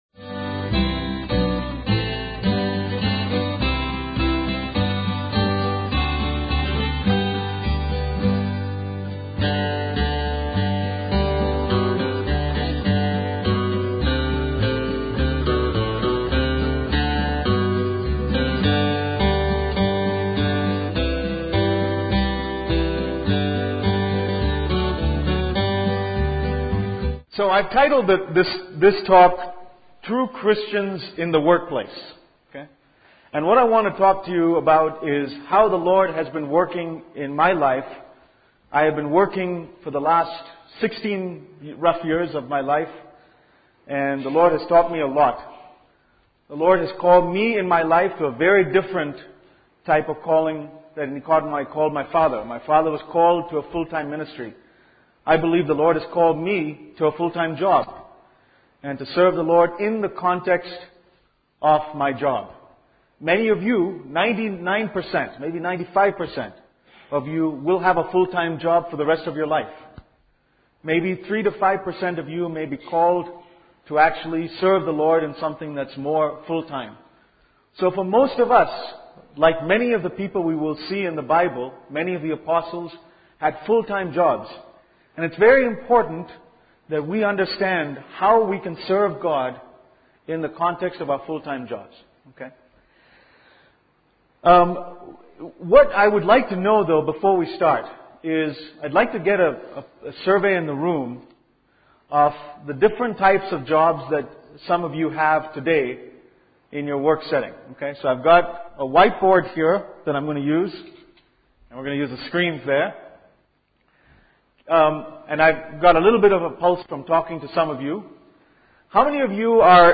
These messages were given at the CFC Youth Camp in December 2007 To view a message, click on the message title.